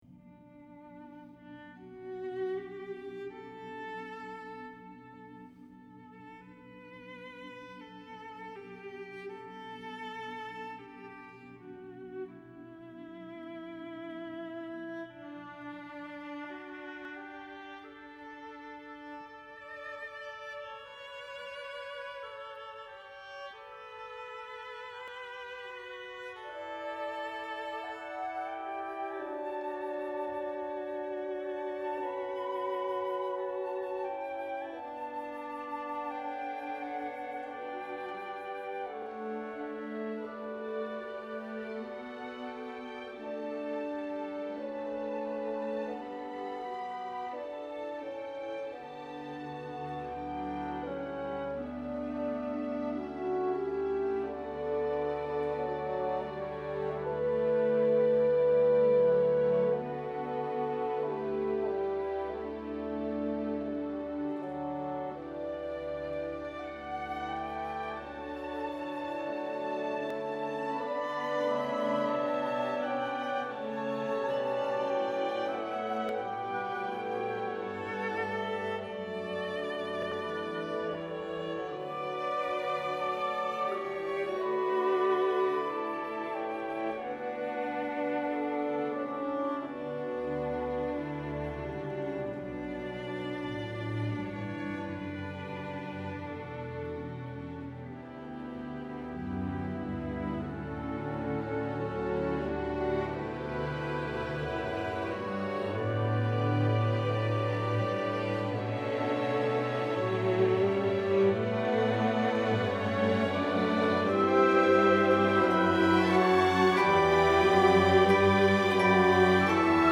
a suite for viola, orchestra, and wordless chorus.